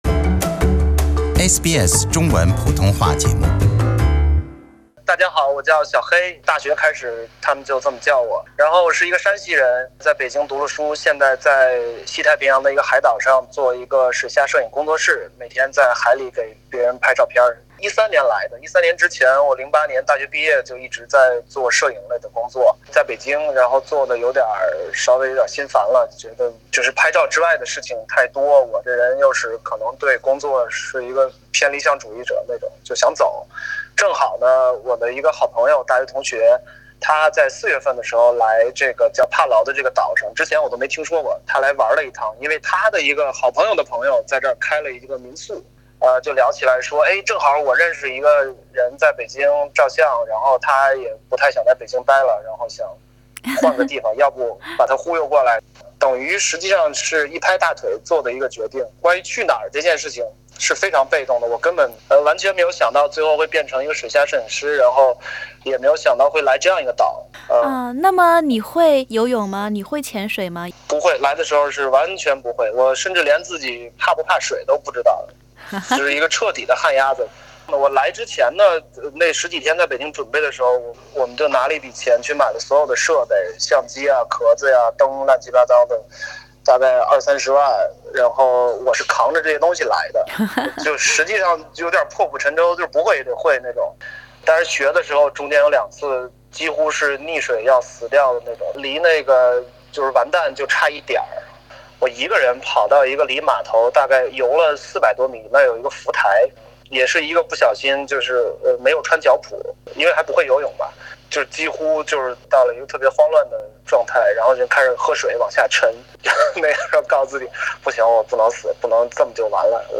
于是我们保留了这段伴着雨声的采访。